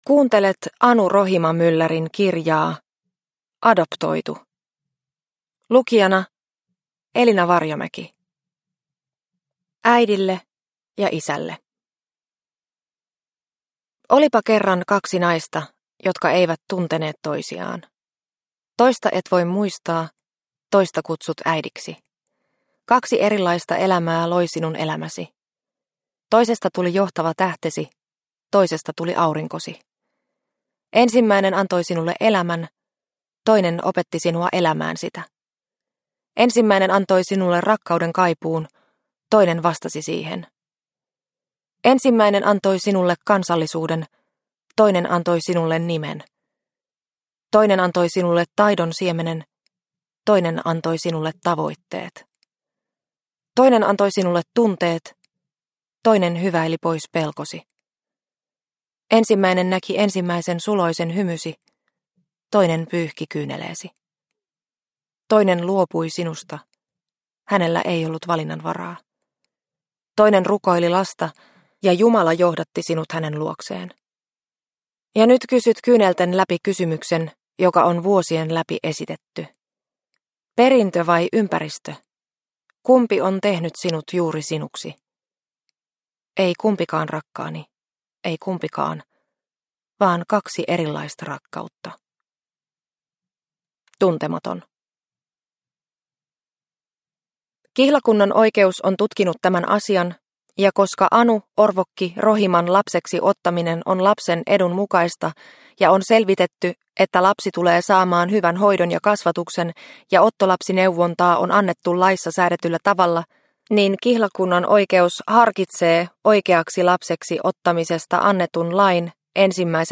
Adoptoitu – Ljudbok – Laddas ner